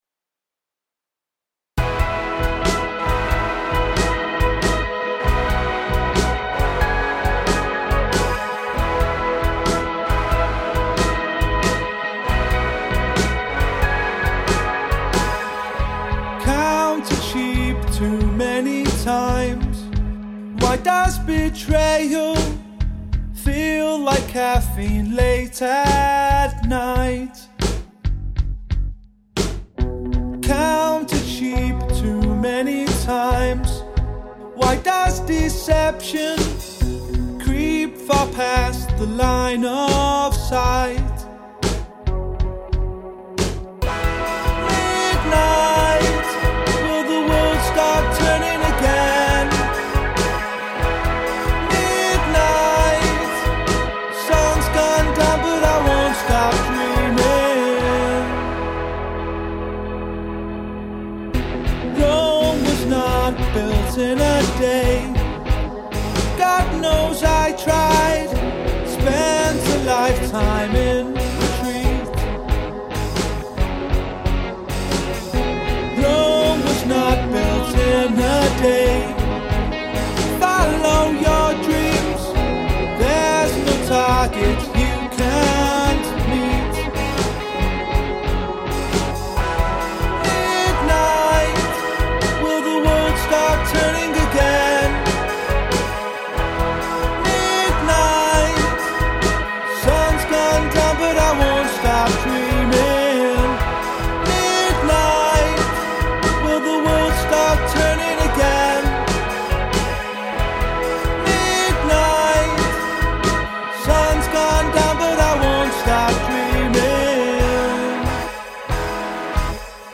was recorded at home in the musician’s home studio